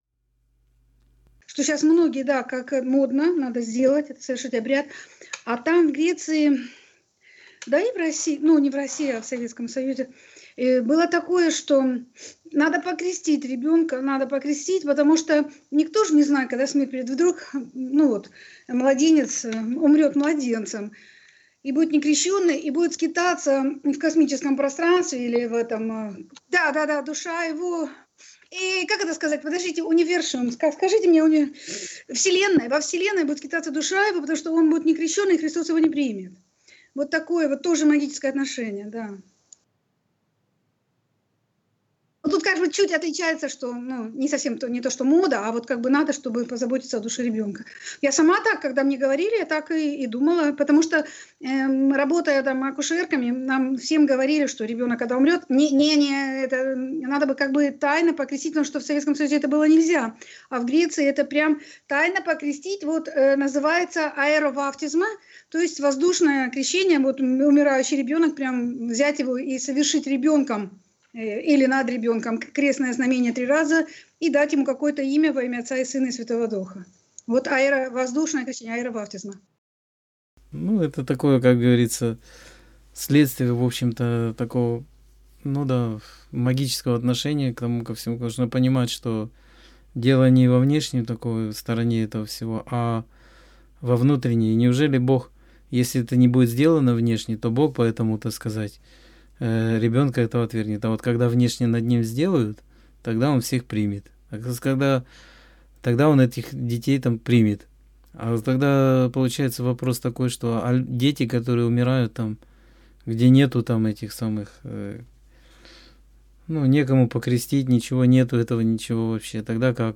Скайп-беседа 12.11.2017